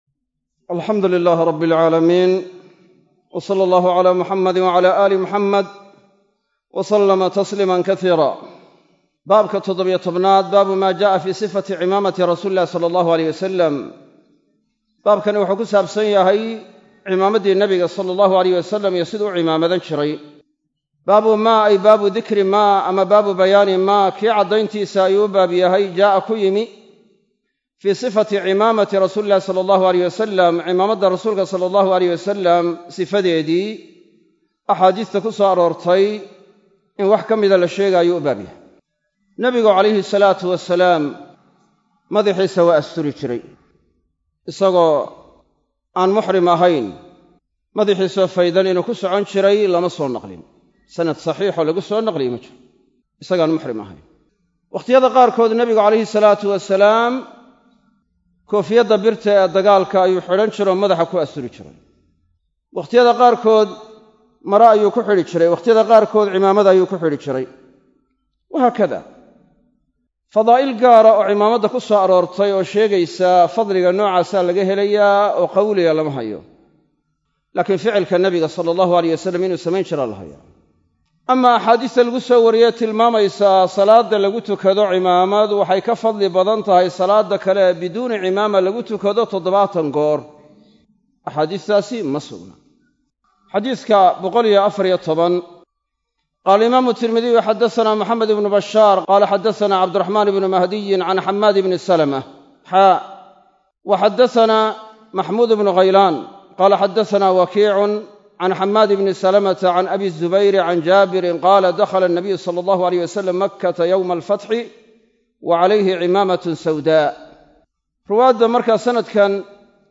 Sharaxa kitaabka Shamaa'il Al-Muxammadiyyah - Darsiga 20aad - Manhaj Online |
Masjid Af-Gooye – Burco